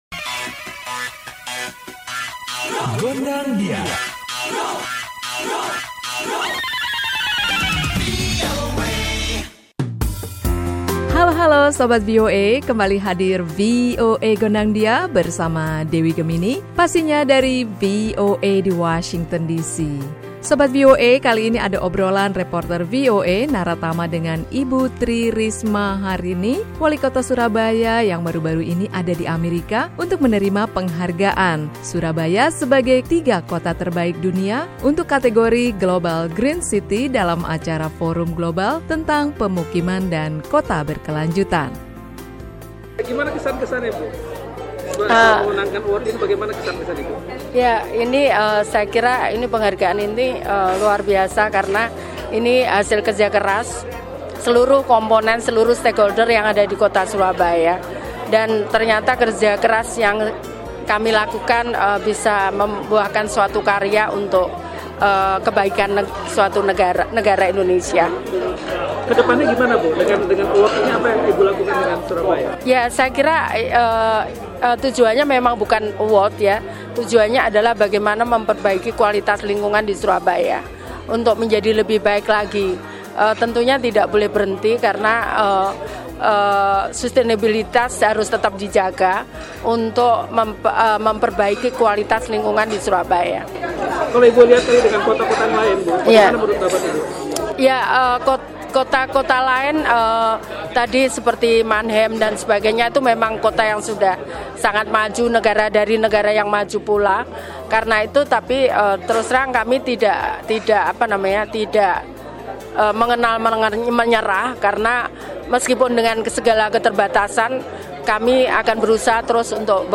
Kali ini ada obrolan dengan Ibu Risma, Walikota Surabaya yang baru-baru ini diundang PBB ke New York untuk menerima penghargaan "Global Green City" bagi kota Surabaya.